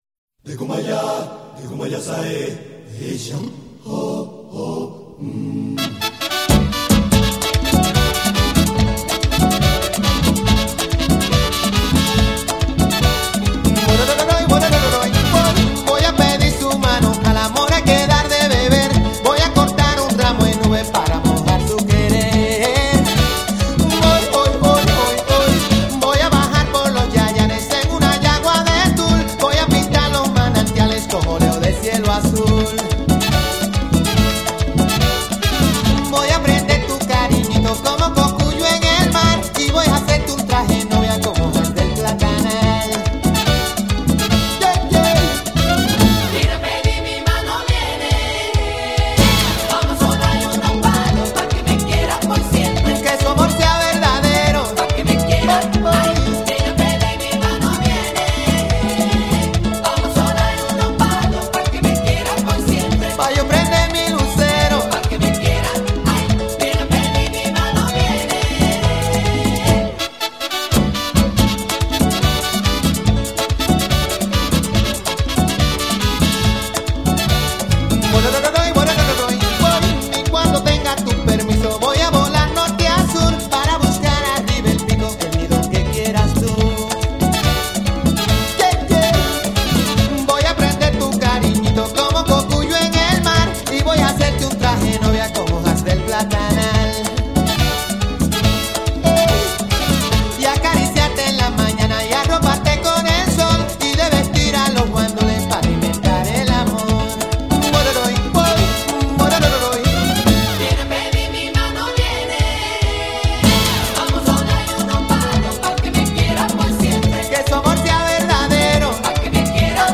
Merengue
Tempo  : 54 - 62 takty na minutę
Takt       : 2/4